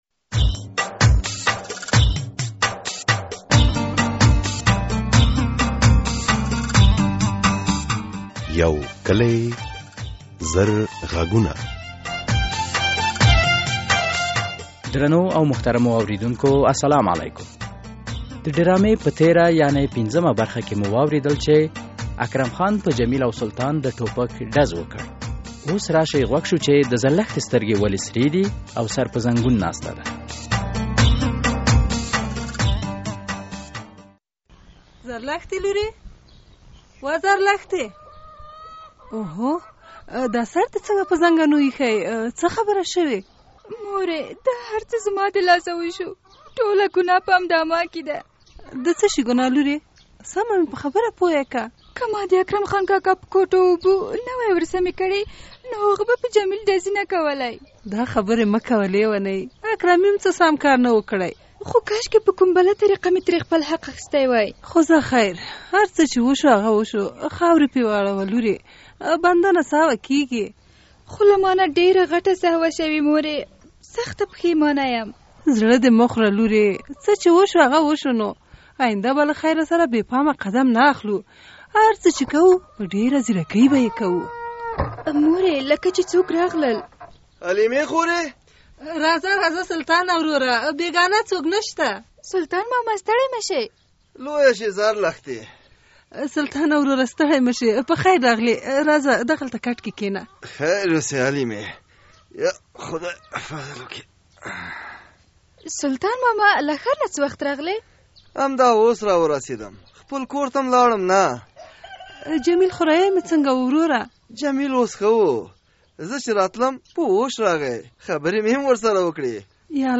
یوکلي او زرغږونه ډرامه هره اونۍ د دوشنبې په ورځ څلور نیمې بجې له ازادي راډیو خپریږي.